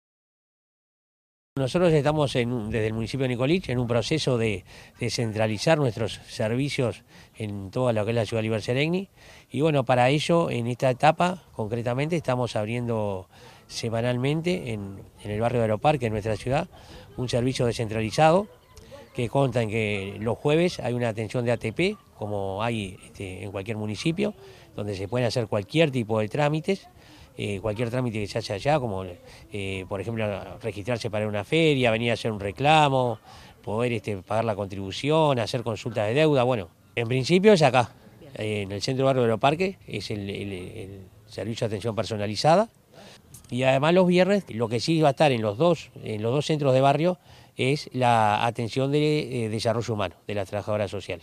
liber_moreno_alcalde_de_nicolich.mp3